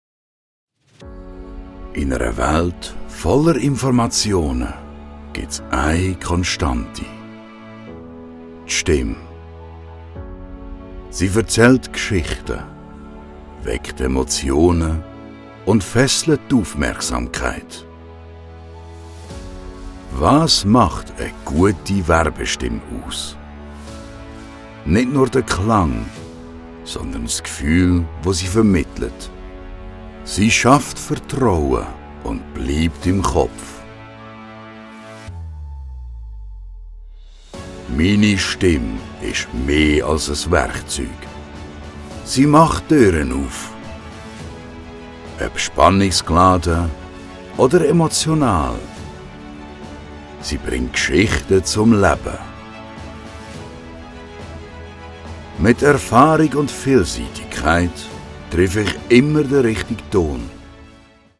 Sprecher mit breitem Einsatzspektrum.